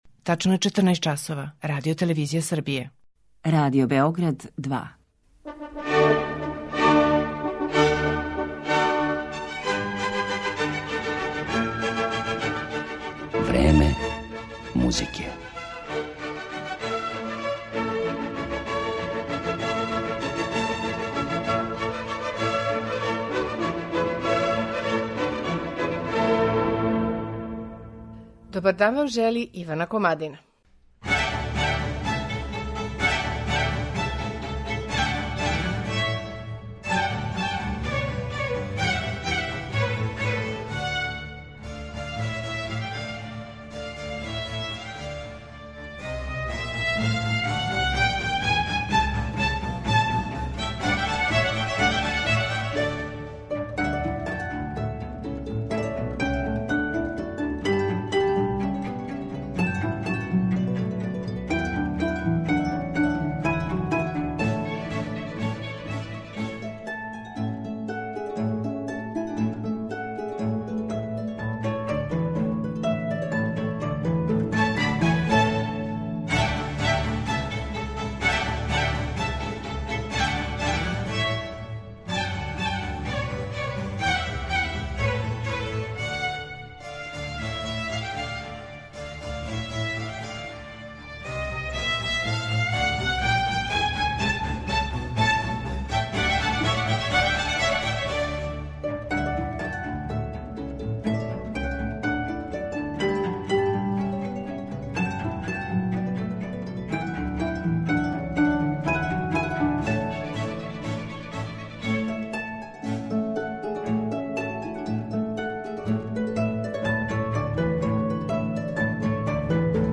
Француски харфиста Гзавије д Местр припада оној елити солиста који редефинишу домете инструмента који су одабрали - истражује и усавршава све његове могућности и ангажује се на проширивању репертоара.